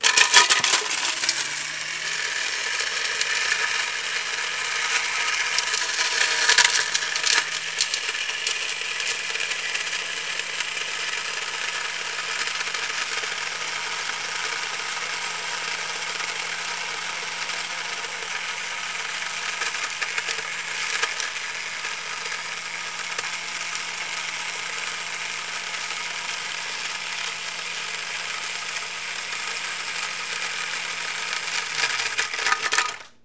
blender.mp3